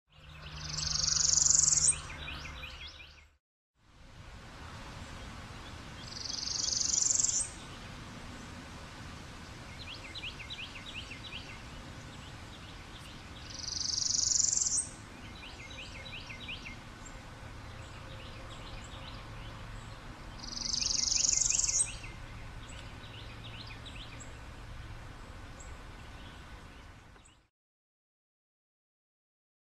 Northern Parula  MOV  MP4  M4ViPOD  WMV